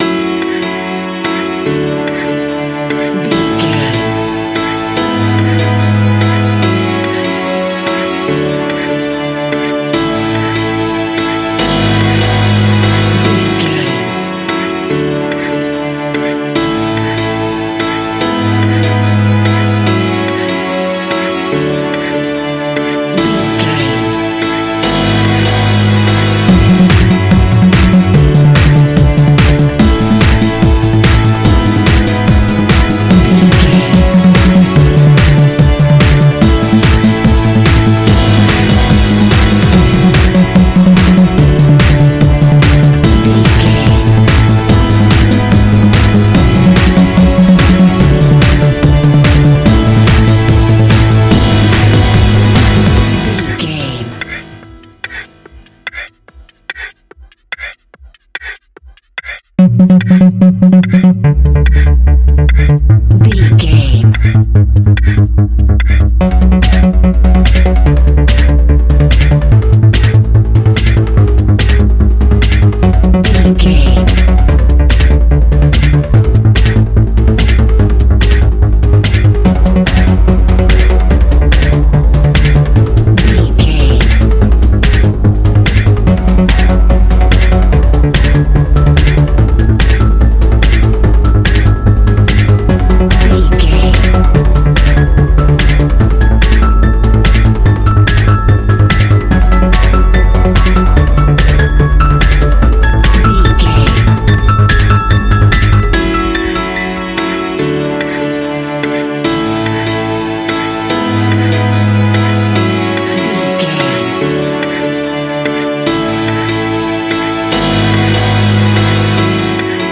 Ionian/Major
energetic
uplifting
futuristic
hypnotic
drum machine
synthesiser
piano
acid house
electronic
uptempo
synth leads
synth bass